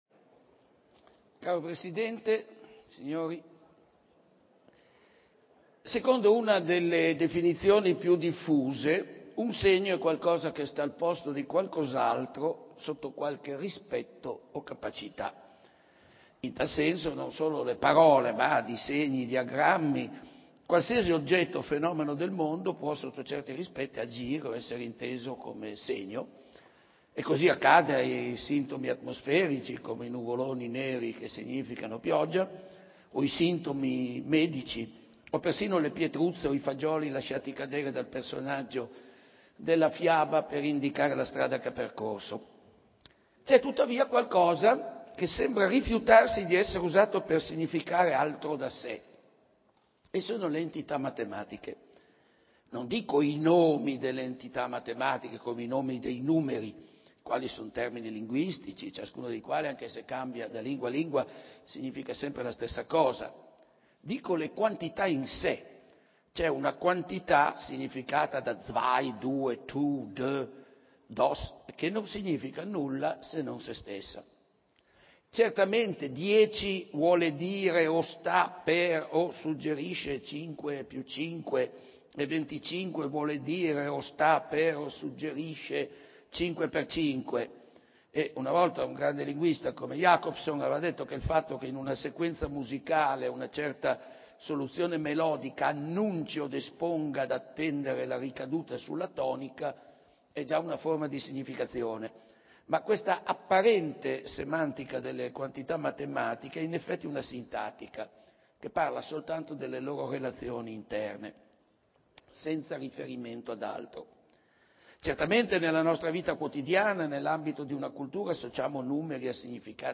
umberto_eco_conferenza.mp3